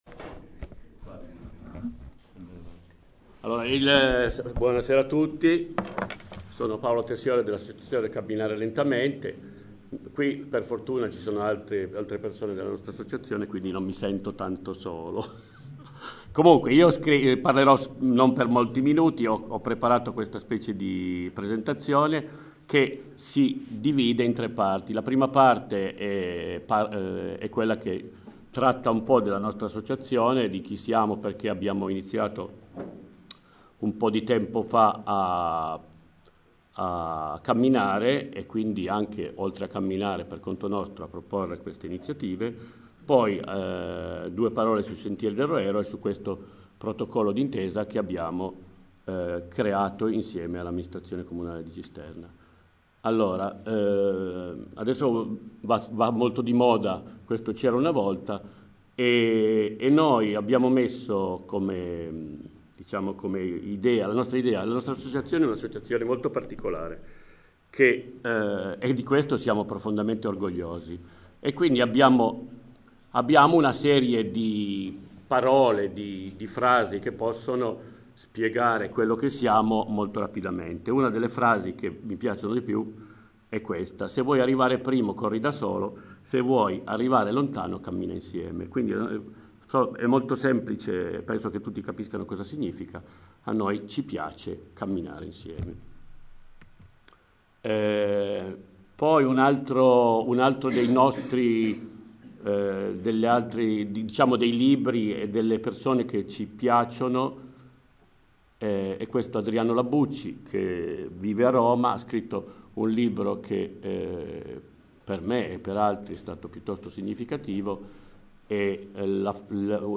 REGISTRAZIONE DELL’INCONTRO IN FORMATO MP3